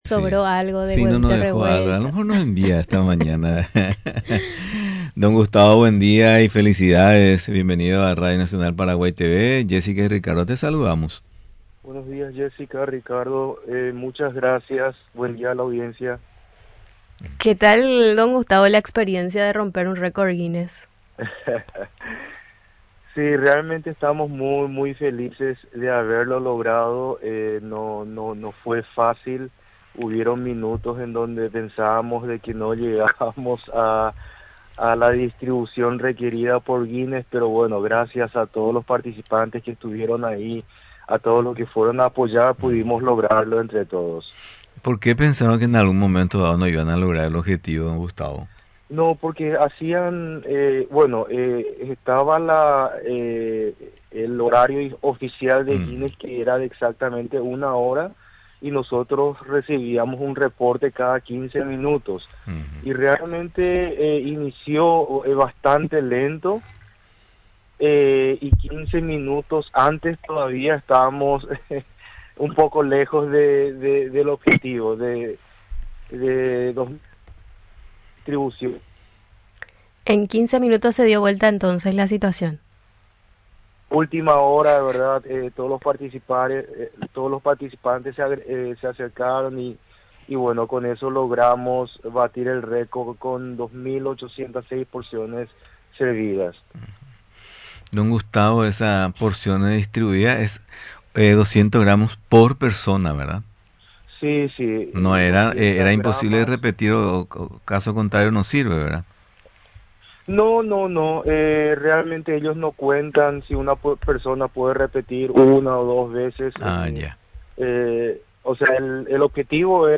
El entrevistado relató que la participación masiva de los asistentes fue clave para que el esfuerzo diera frutos, permitiendo que la porción de 200 gramos por persona se distribuyera a tiempo, asegurando la consecución del récord.